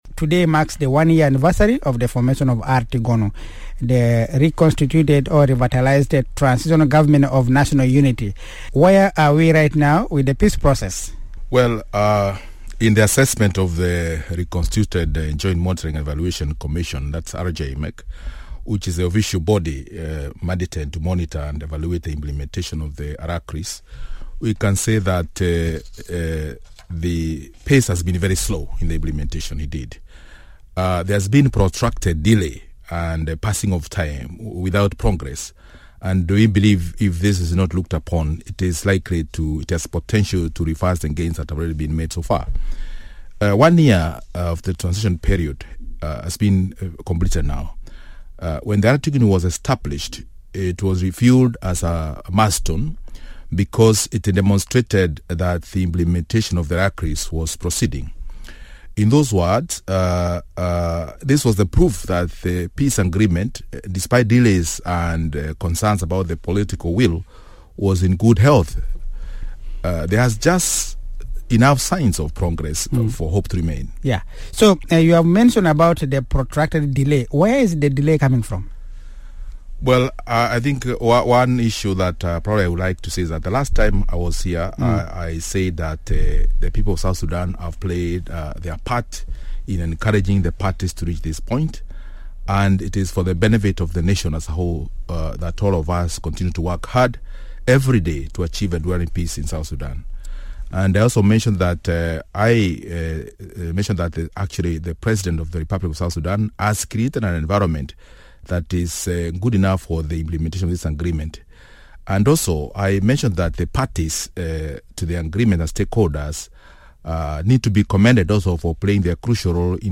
Discussion: R-JMEC reflection on one-year anniversary of R-TGoNU